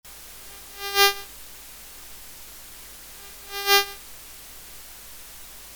Странный звук: SSL 2+, Cloudfilter, Shure SM7B, Ноутбук DELL (WIndows).
Карта SSL 2+ + Cloudfilter + SM7B.
При подключении шнура от усилителя с микрофоном к карте, даже если микрофон не включать на звуковухе, появляется какой-то противный звук периодически на пол секунды, он фиксируется даже во время записи.